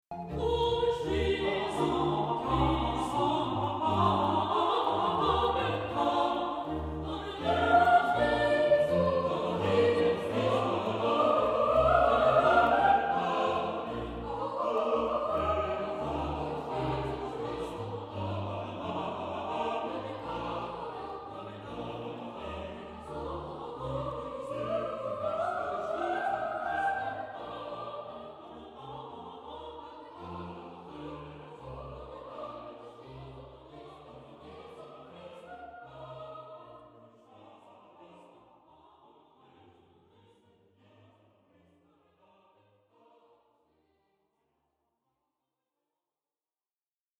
Der zweite Teil ist eine Fuge mit dem Text „Durch Jesum Christum, Amen“.
Im Schussteil ist sehr viel Zuversicht zu hören. Es ist eine Fuge mit zwei Themen.
Sehr spielerisch wechseln diese Koalitionen in der Schlussfuge.
Die Besetzung ist auch ganz dem Casus der Trauer verpflichtet und dabei bei Bach einmalig: Es gibt zwei Blockflöten, zwei Gamben und Continuo (also noch Kontrabass und Tasteninstrument: Cembalo und/oder Orgel).